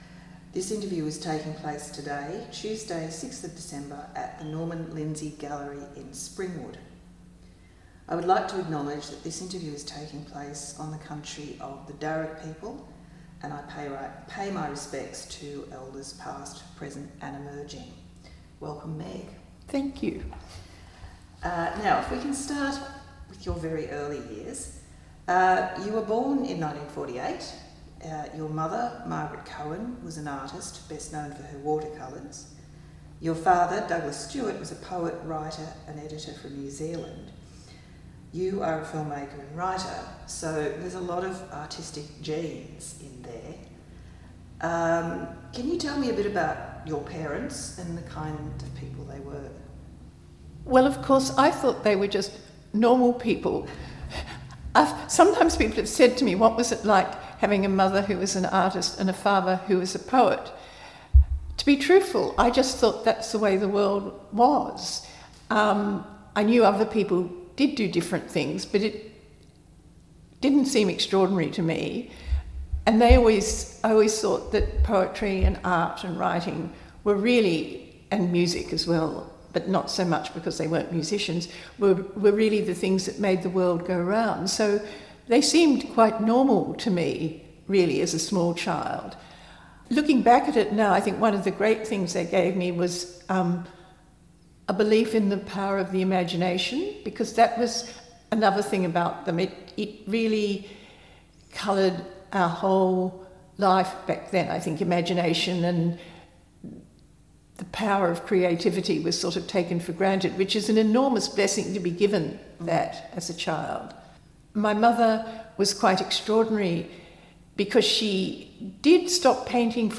Each of these oral histories delve into a person’s life story, and are recorded as a long-form interview with few set questions.